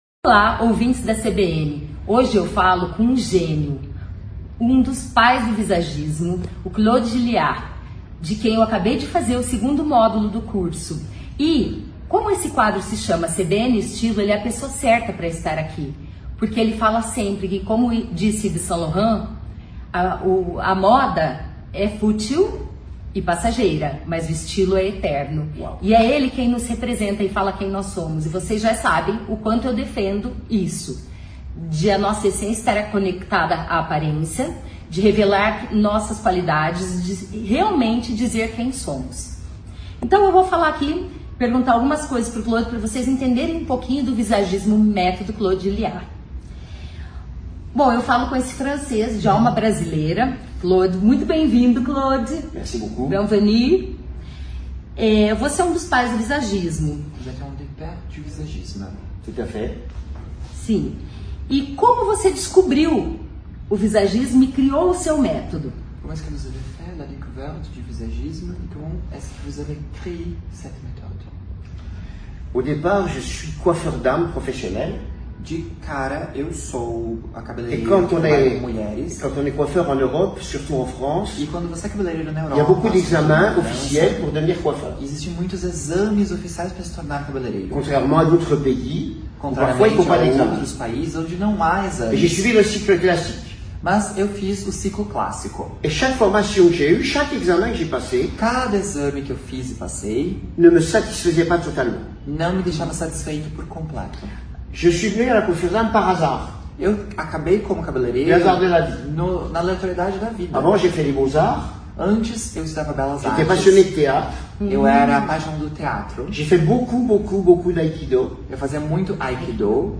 conversa com o Visagista